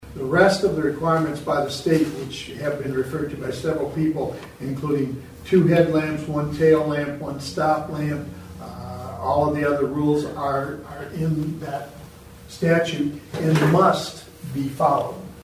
City Attorney Chuck Lillis told the Council a golf cart ordinance must follow state guidelines.